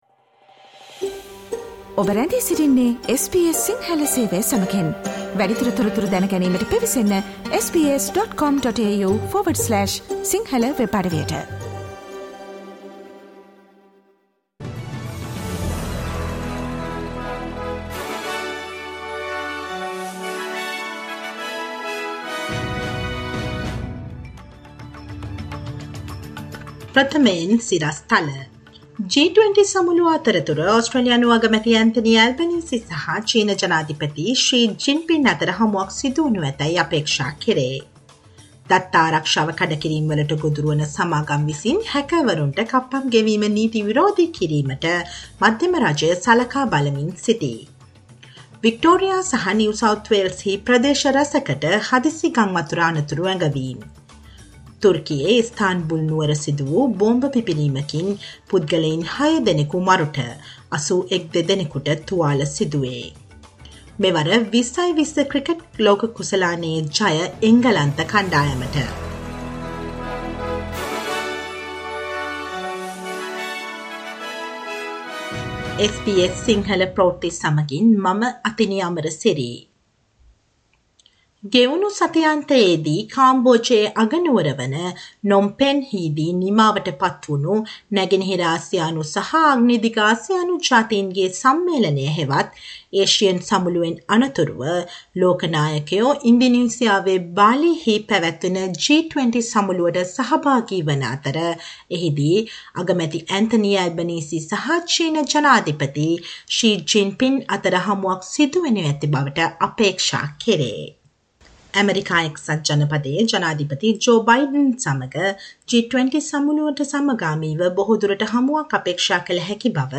Listen to the SBS Sinhala Radio news bulletin on Monday 14 November 2022